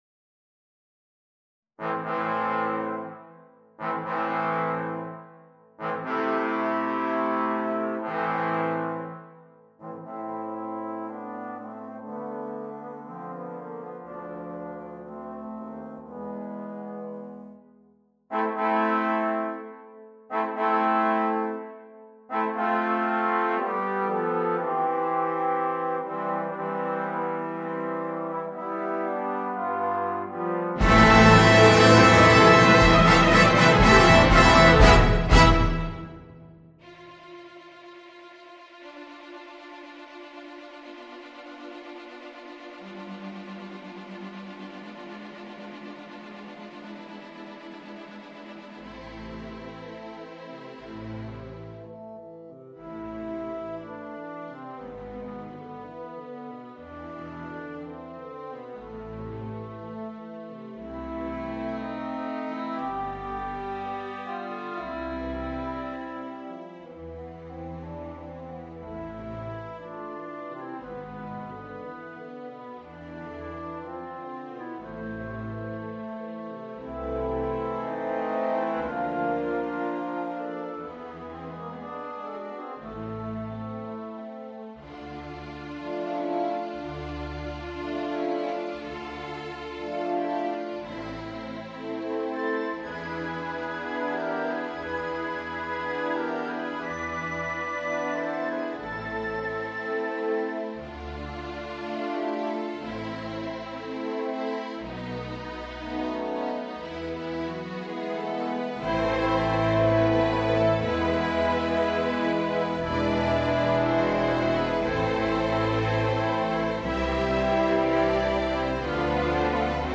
Solo work
without solo instrument
Classical, Classical Overture
Wood Wind 2 / 2 / 2 / 2
Brass 4 / 2 / 3 / 0
Timpani / Percussion 3